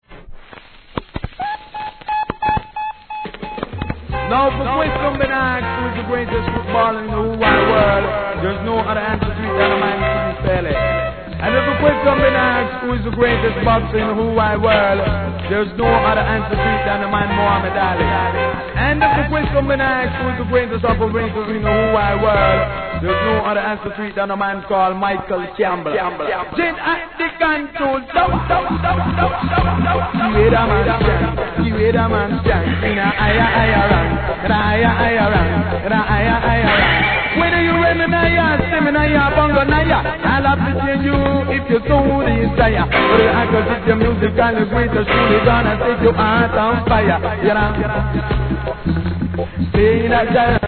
序盤に周期的なプツ入りますが消えます
REGGAE